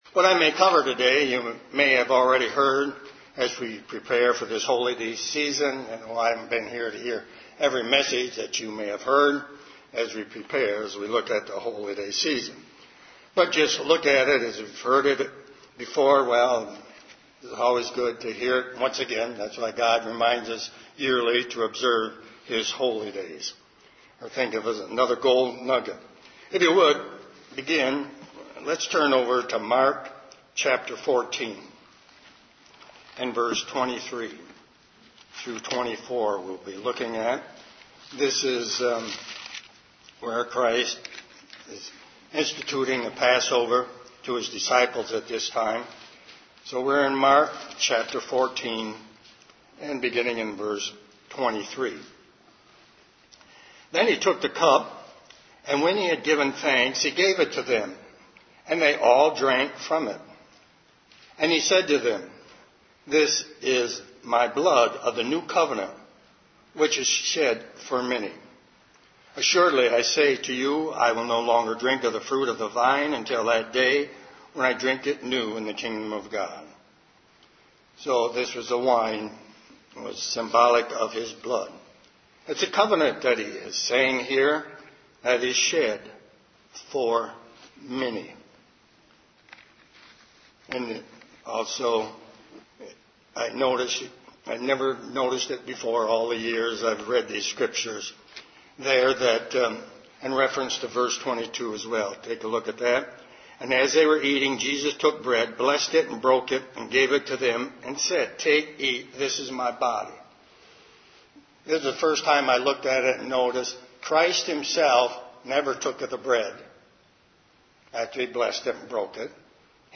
Given in Detroit, MI